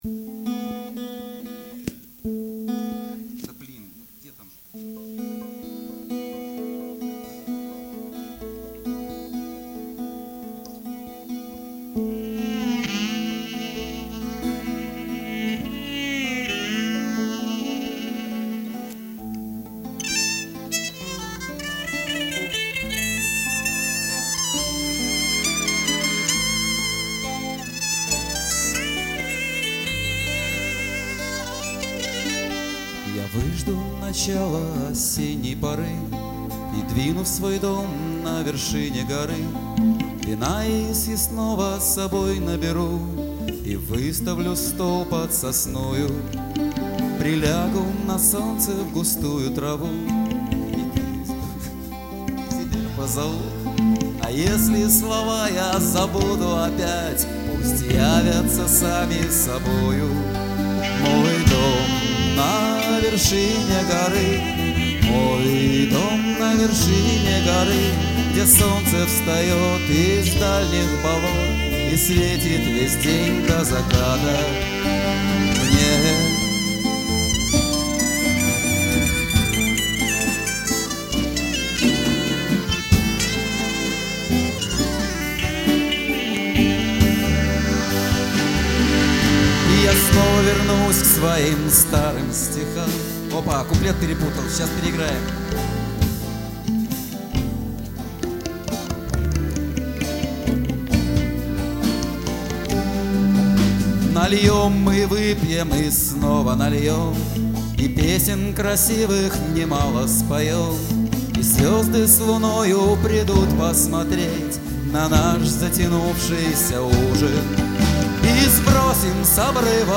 Live в клубе "Манхэттен", 11.07.2007